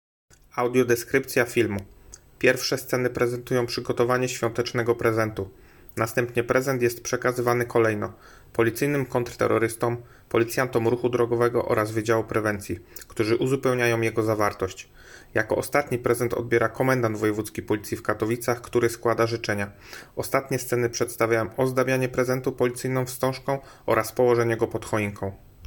Nagranie audio audiodeskrypcja_filmu.m4a